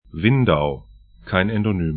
Windau 'vɪndau Ventspils 'vɛntspɪls lv Stadt / town 57°24'N, 21°36'E